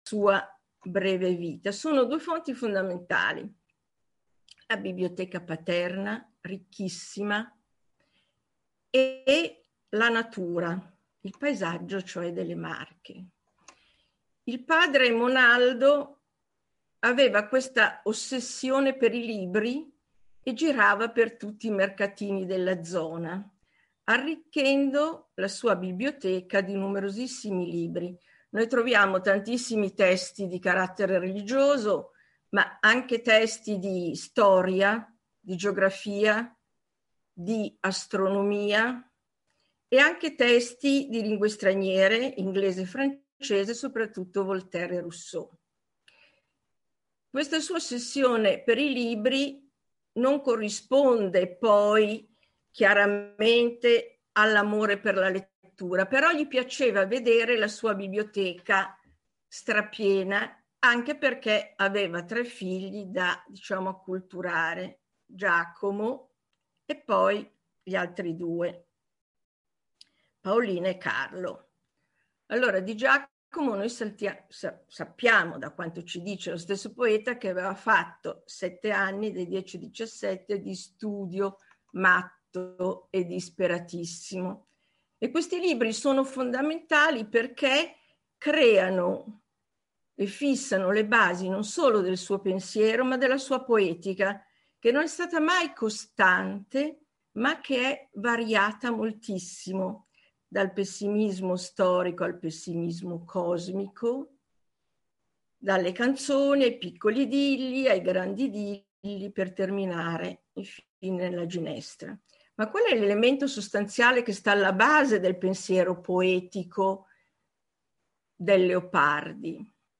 ZOOM Meeting